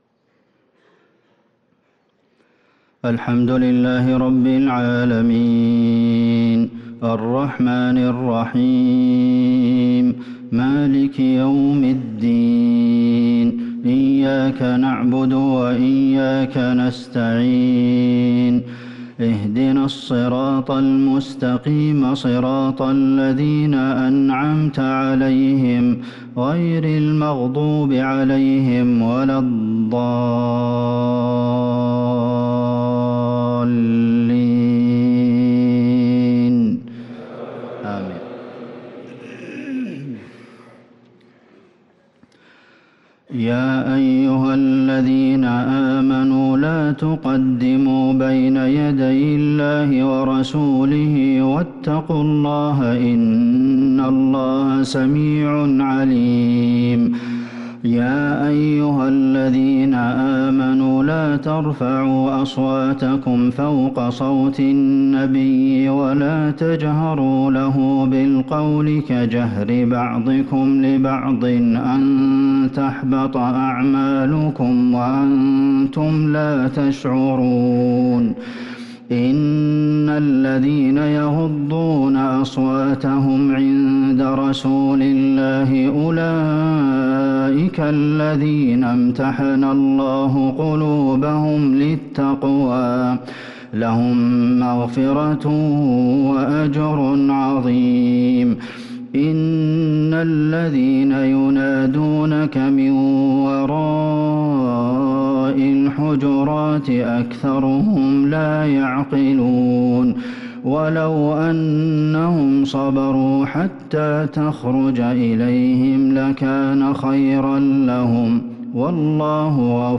صلاة العشاء للقارئ عبدالمحسن القاسم 15 جمادي الآخر 1444 هـ
تِلَاوَات الْحَرَمَيْن .